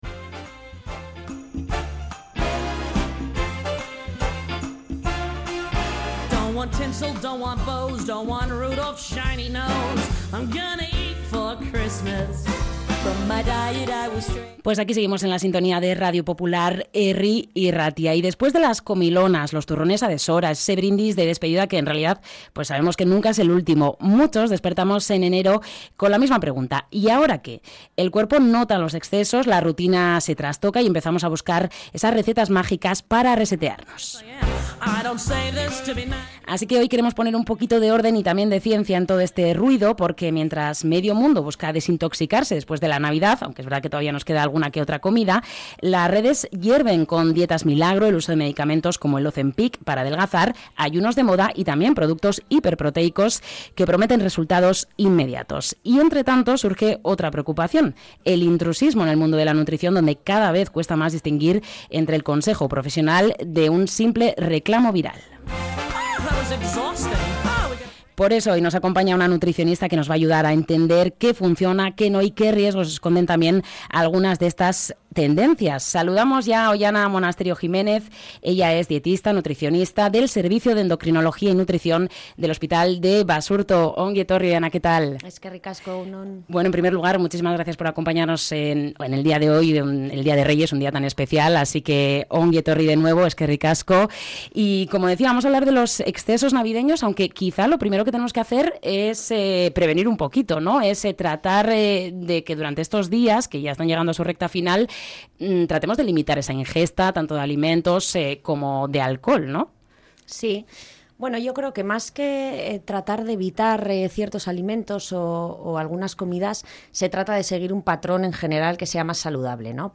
Repasamos con una nutricionista mitos y verdades tras los excesos navideños